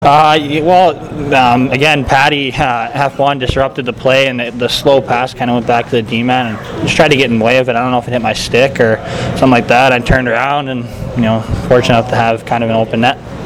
After the game I was in a full sprint to get my story up – get to and through interviews fast – all before Gavin DeGraw’s concert drowned out the BC with loud noises.